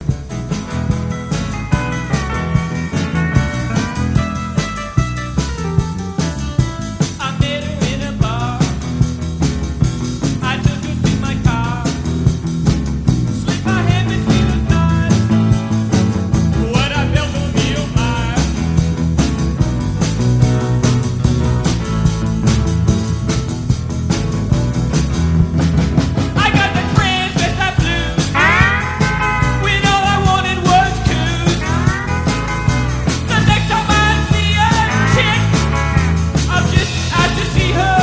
slide guitar
electric piano
on bass